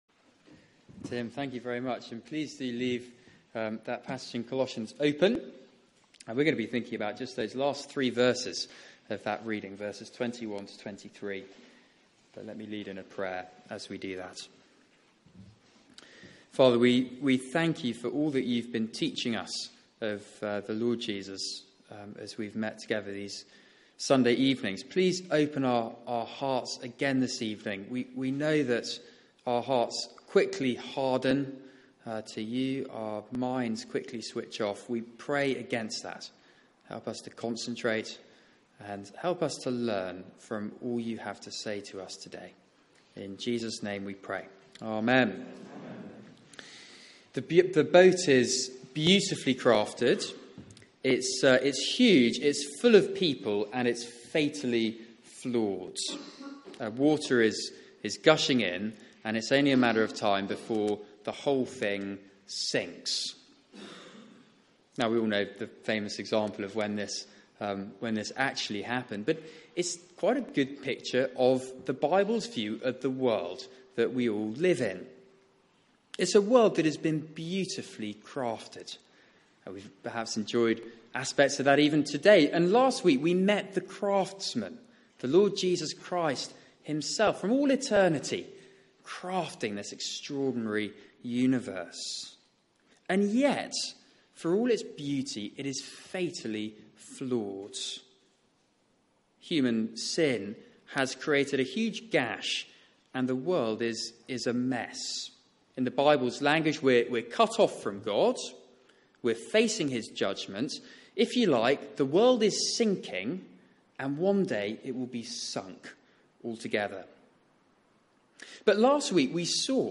Media for 6:30pm Service on Sun 16th Oct 2016 18:30 Speaker
Series: Rooted in Christ Theme: How Christ brings us to God Sermon